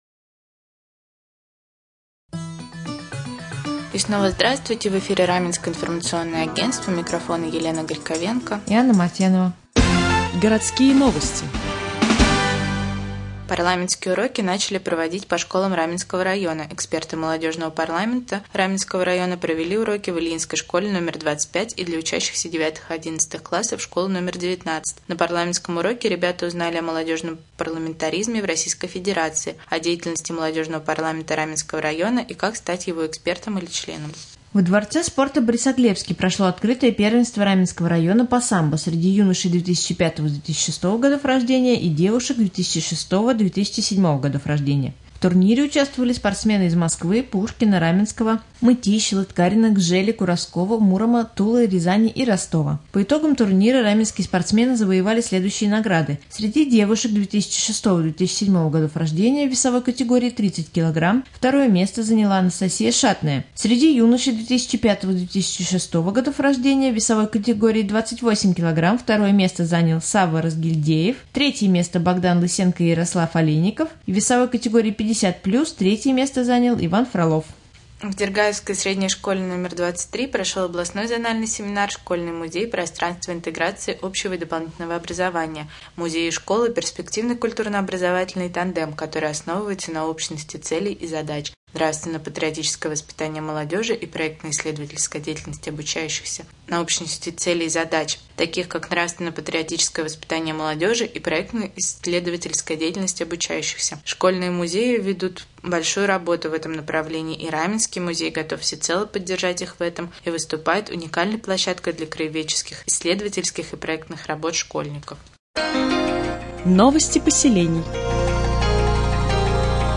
Сегодня в новостном выпуске на Раменском радио Вы узнаете, когда в Раменском пройдет прием граждан по вопросам здравоохранения, где можно научиться актерскому мастерству и ораторскому искусству, какие брошенные автомобили администрация переместит в специализированные места хранения, а также последние областные новости и новости соседних районов.